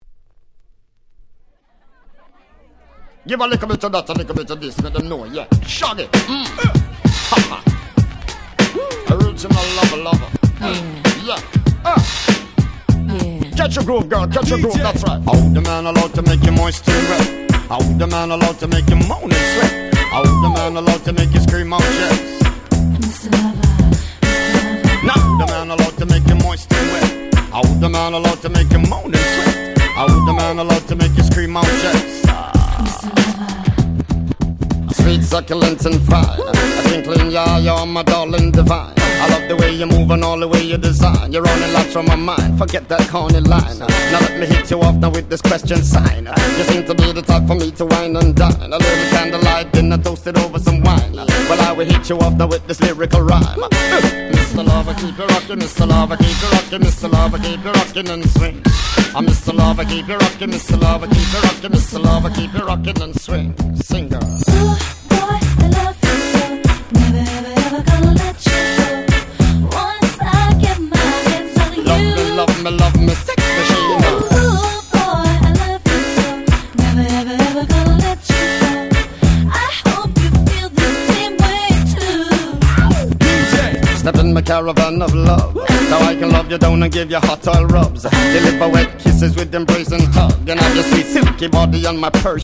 01' Super Hit Reggae !!